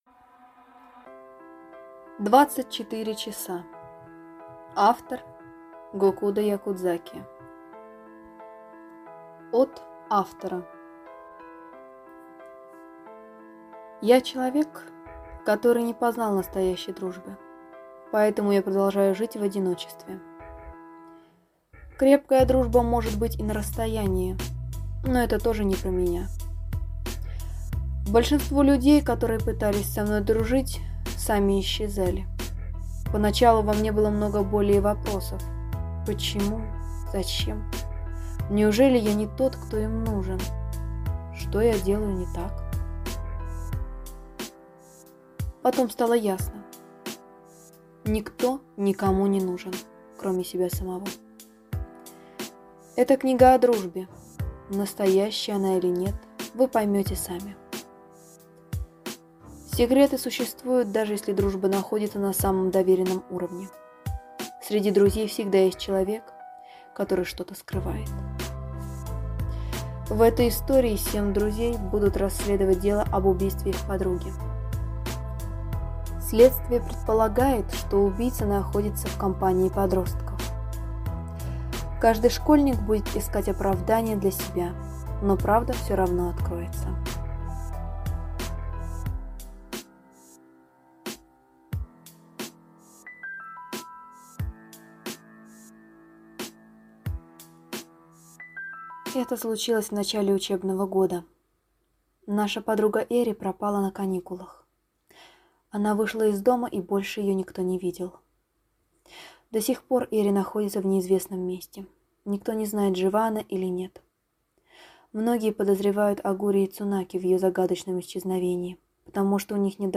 Аудиокнига 24 hours | Библиотека аудиокниг